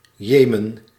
Ääntäminen
Ääntäminen US Tuntematon aksentti: IPA : /ˈjɛmən/ Haettu sana löytyi näillä lähdekielillä: englanti Käännös Ääninäyte Erisnimet 1.